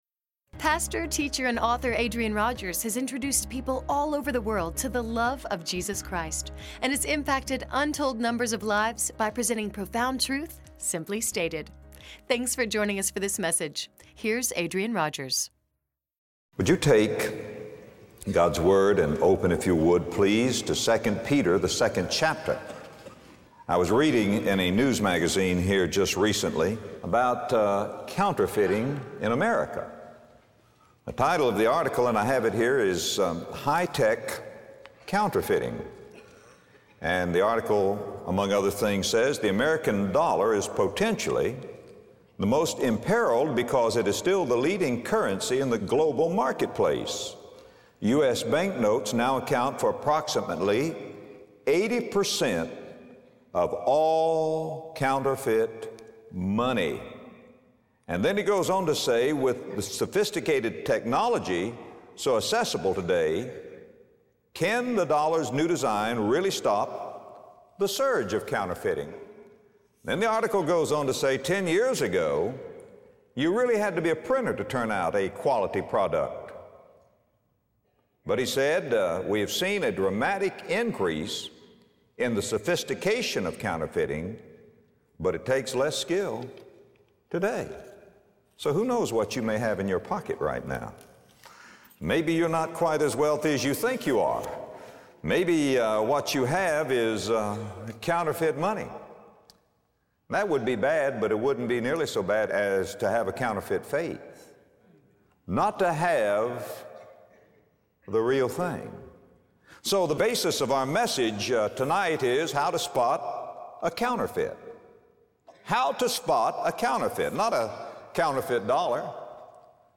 Scripture tells us that false teachers are among us and warns us of the dangers of their doctrine. In this message, Adrian Rogers reveals how to spot a counterfeit teacher.